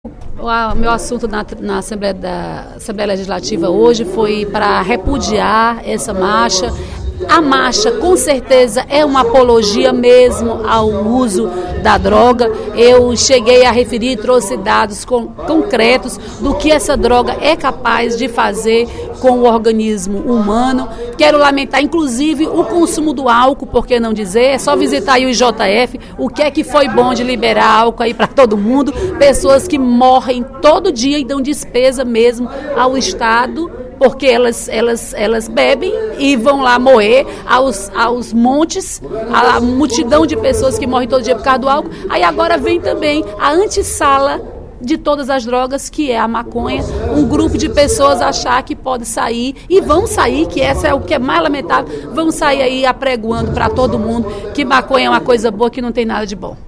No primeiro expediente da sessão plenária desta sexta-feira (24/05), a deputada Dra. Silvana (PMDB) repudiou a Marcha da Maconha, movimento que luta pela legalização da maconha no Brasil.